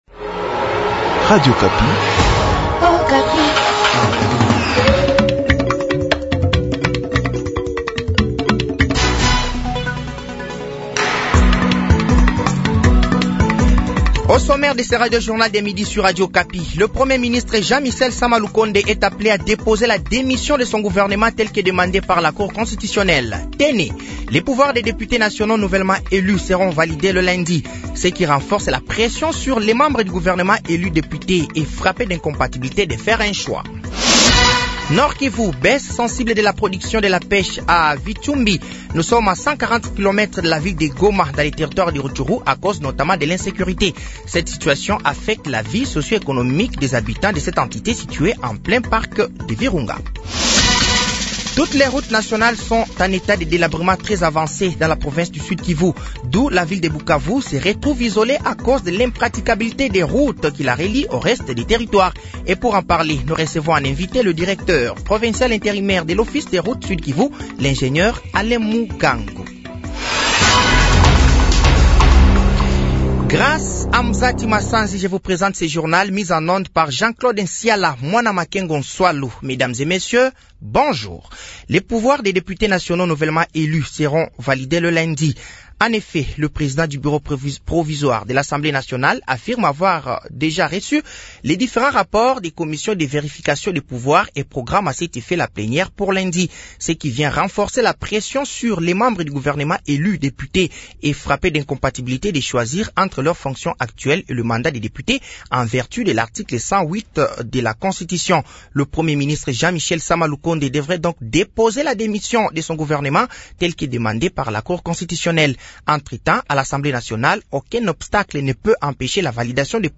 Journal français de 12h de ce vendredi 09 février 2024